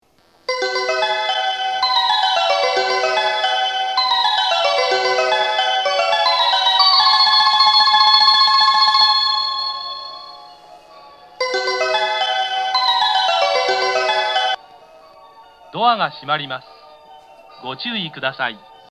2009年2月頃に放送装置が更新され、音質が向上しました。
発車メロディー
1.5コーラスです!日中でも余韻までなら鳴りやすいです。